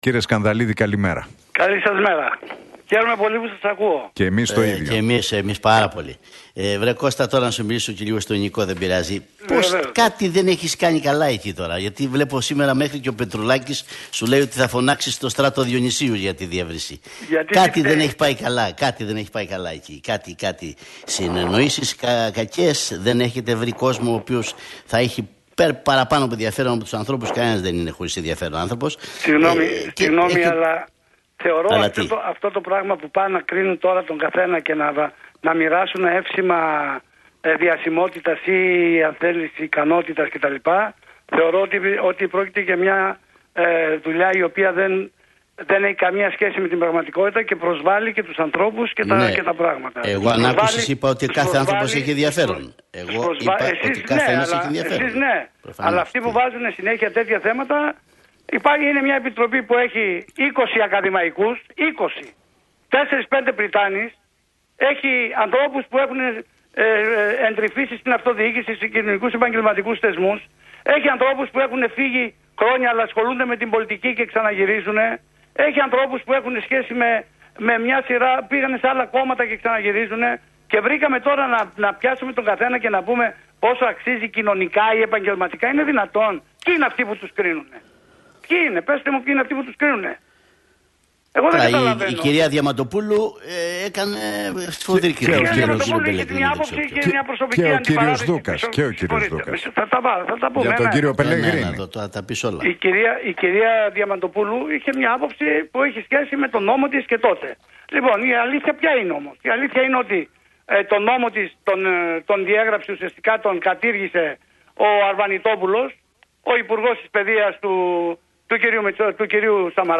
Σκανδαλίδης στον Realfm 97,8 για τη διεύρυνση: Φοβούνται ότι θα ξυπνήσει αυτός ο γίγαντας που λέγεται ΠΑΣΟΚ